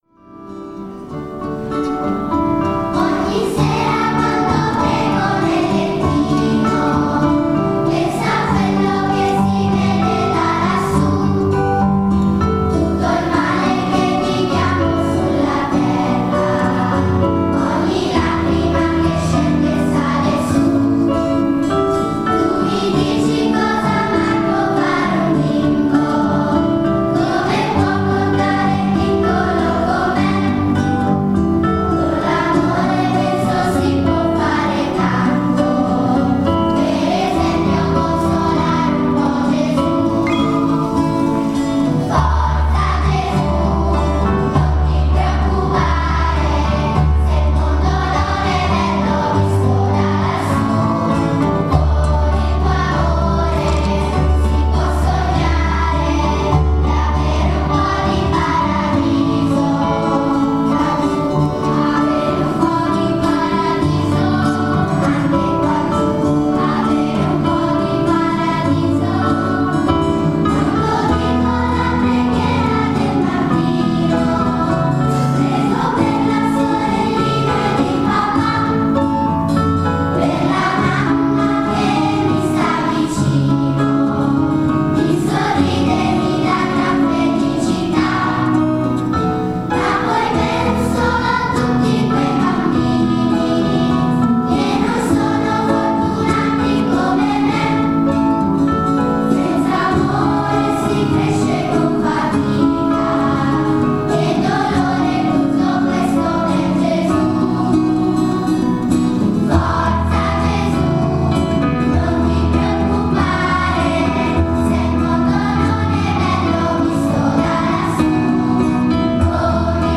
Si è tenuto nella Pieve di San Faustino il tradizionale concerto di natale con la partecipazione delle corali di Rubiera, San Faustino e Bagno dirette da